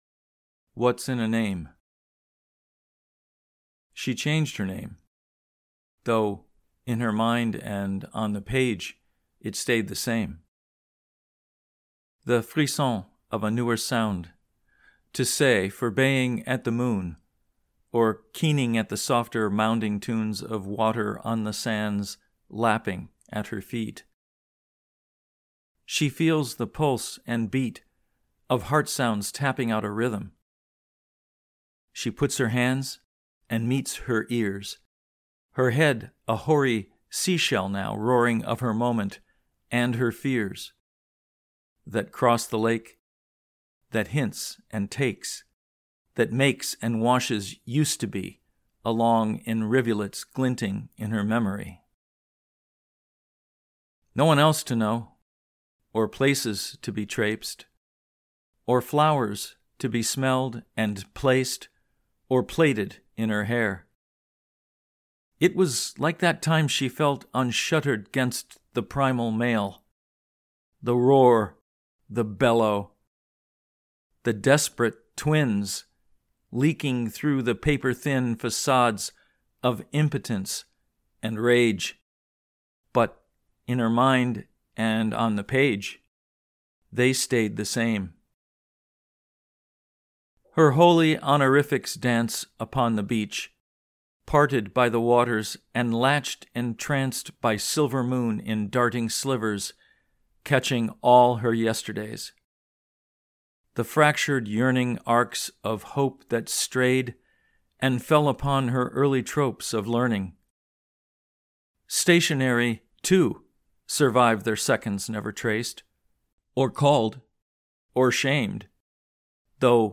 What’s in a Name (Recitation)